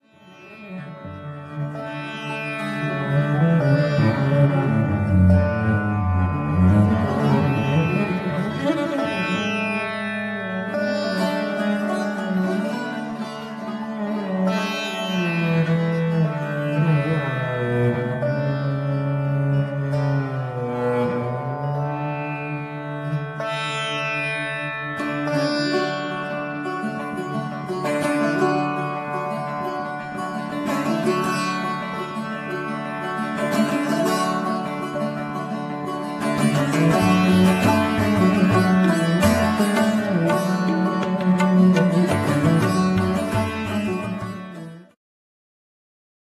wiolonczela, sarangi, ¶piew alikwotowy
`ud, fujara słowacka, lira korbowa, drumla, duduk, głosy
tabla, bendir
baglama, saz, tanbur, ¶piew gardłowy
lira korbowa, ney, kaval
suka biłgorajska, tanpura, głosy